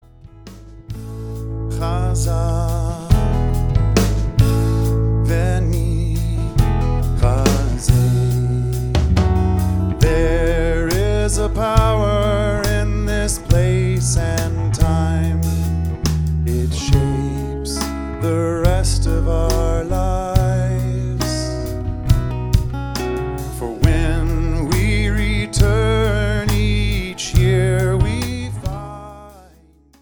Live in front of a studio audience